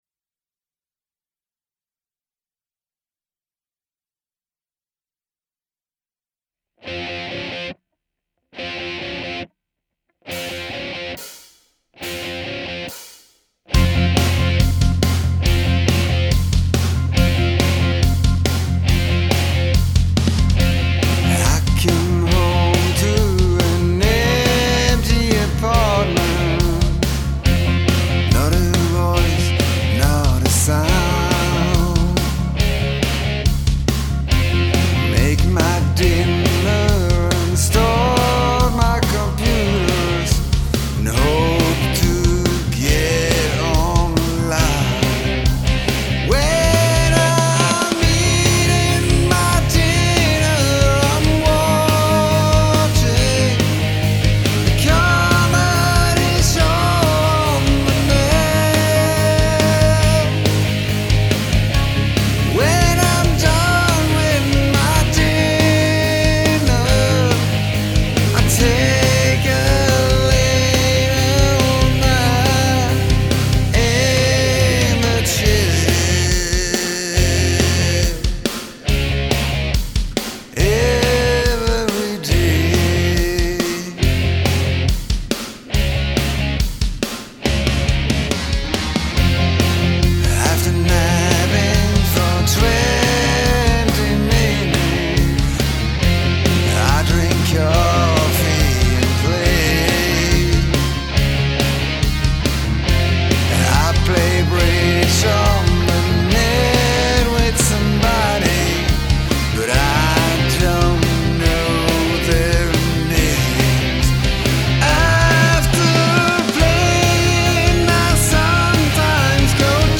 Mix a rock song with blues theme...?
here's my go, I did this mix in about 20 minutes, it was a fast one. There was a problem with cubase though, it didn't print a long delay effect I had in the choruses for some reason, even though everything was set correctly... therefore this is the 'rough' or should I say dryer version!